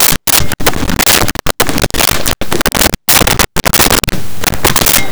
Briefcase Open Close
Briefcase Open Close.wav